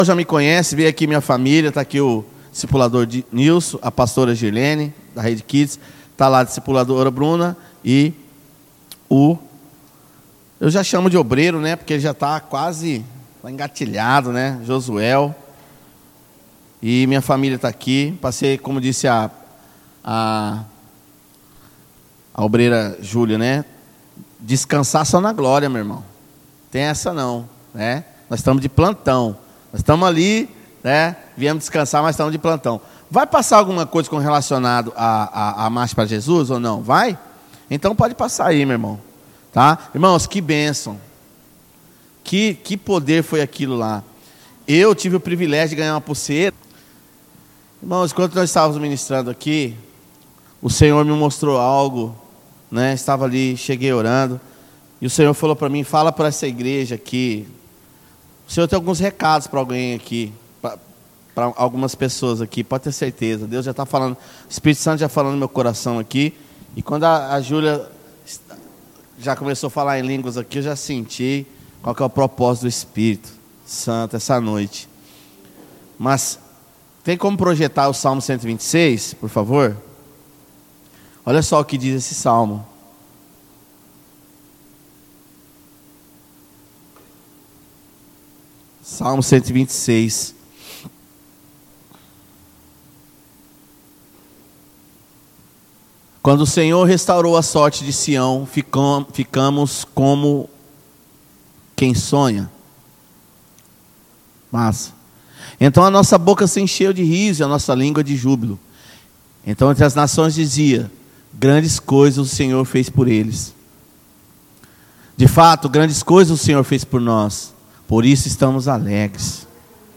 Em Culto de Celebração